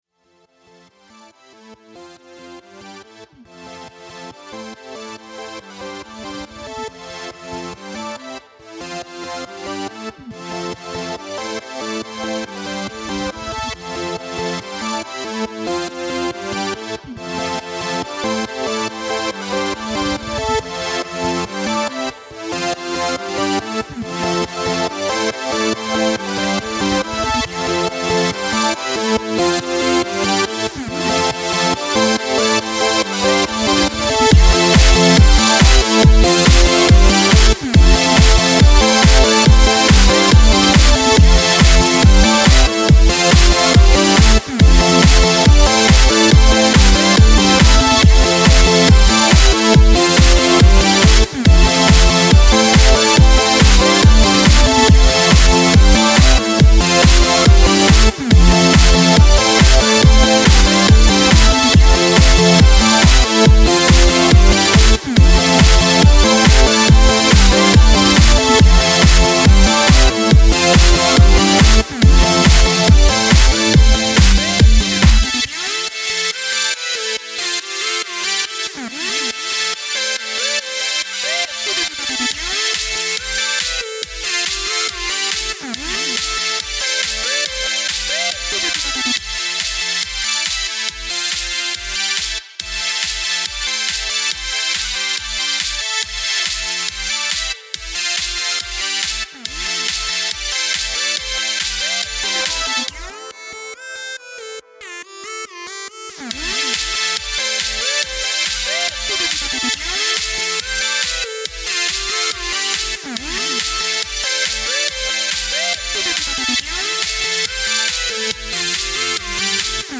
Another electronic track from my last small platform game :))